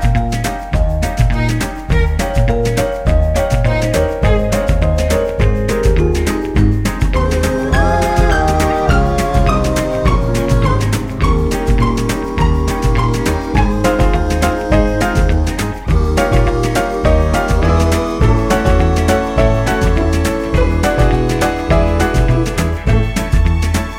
no Backing Vocals Country (Female) 2:05 Buy £1.50